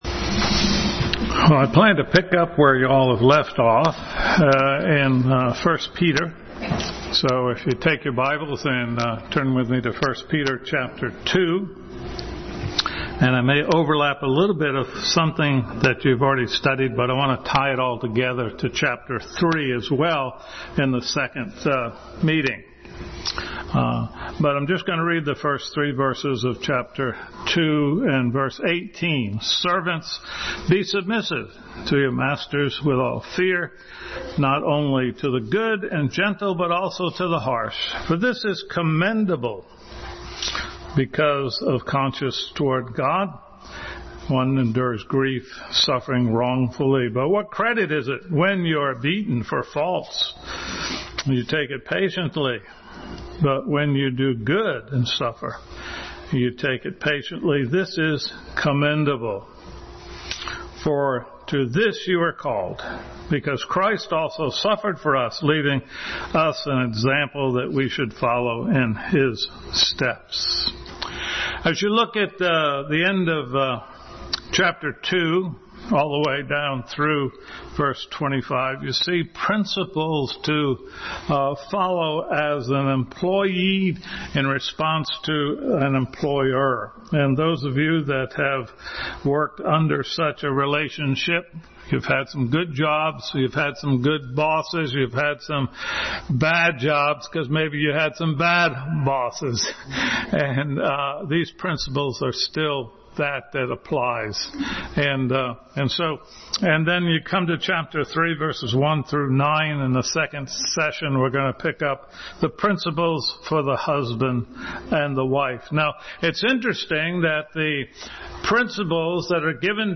1 Peter 2:18-25 Passage: 1 Peter 2:18-25, 1 Corinthians 13:4-7, Ephesians 5:22-32 Service Type: Sunday School